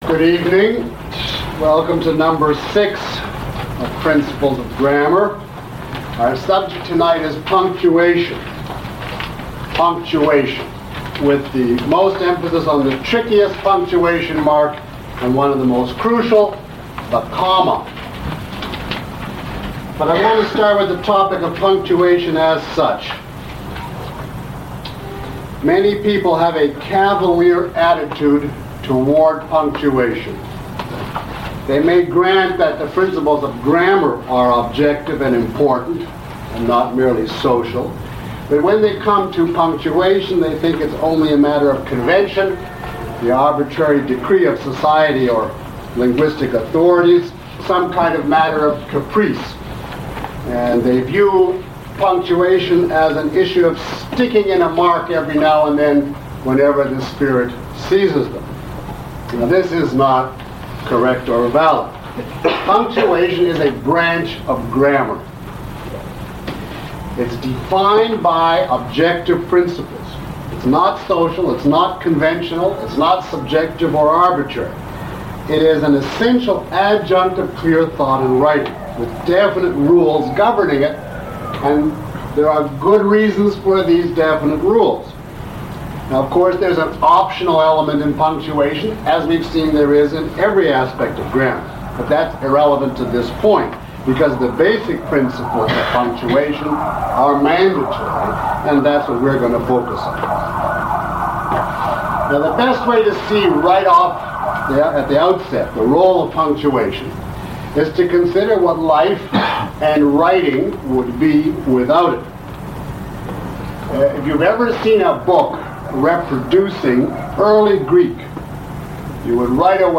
Lecture 06 - Principles of Grammar.mp3